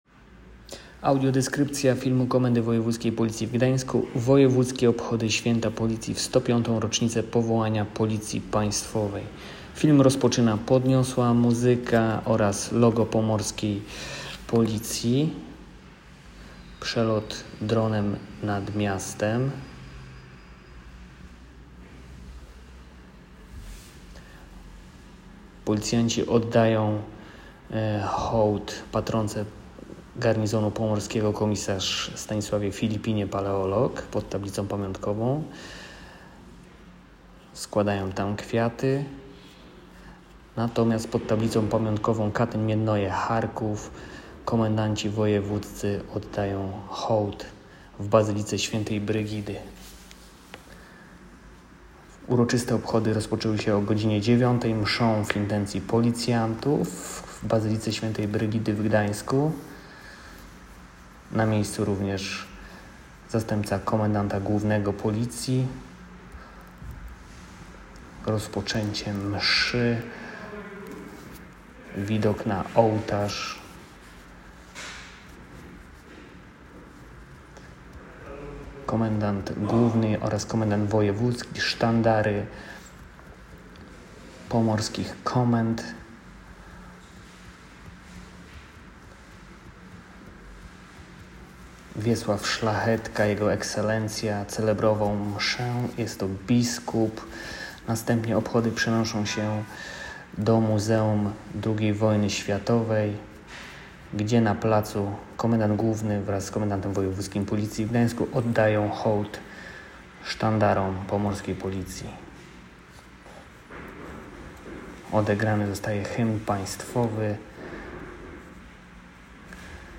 Nagranie audio Audiodyskrypcja.m4a